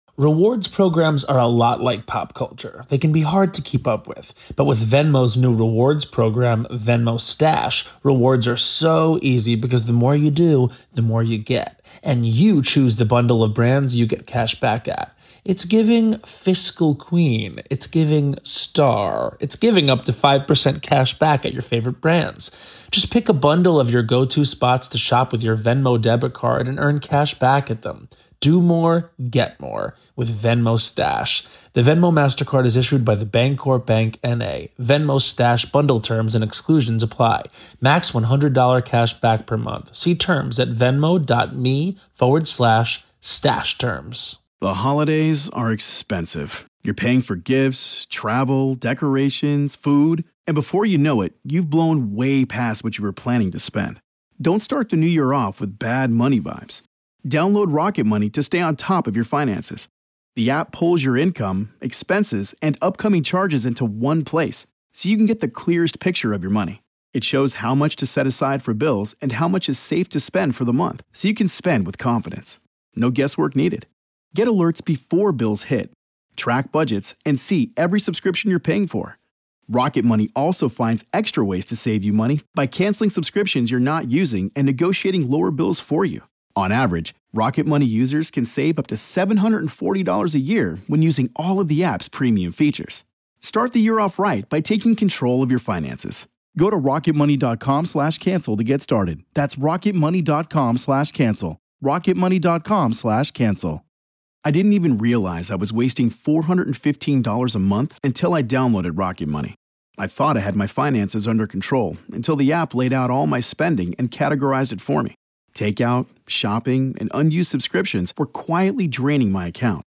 From the best supernatural & paranormal podcast, Real Ghost Stories Online! Haunting real ghost stories told by the very people who experienced these very real ghost stories.